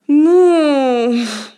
Suspiro de desaprobación
suspiro
mujer
Sonidos: Acciones humanas
Sonidos: Voz humana